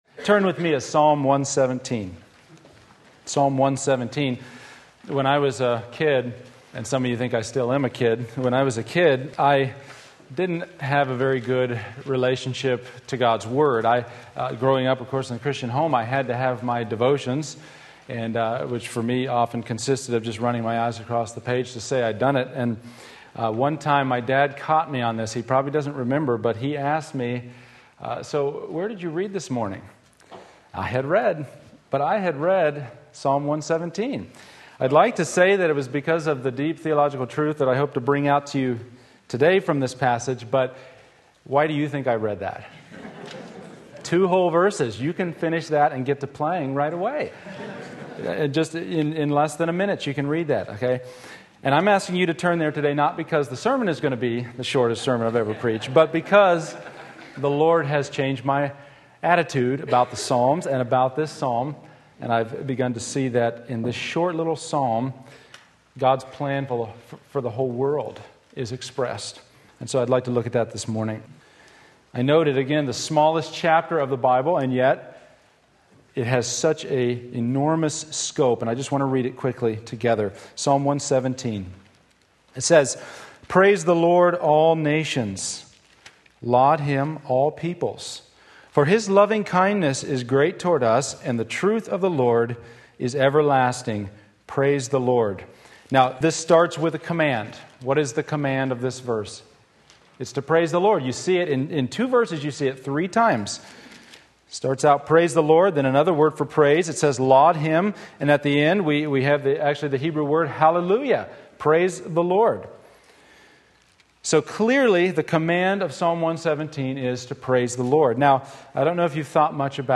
Sermon Link
Praise Him All Nations Psalm 117 Sunday Morning Service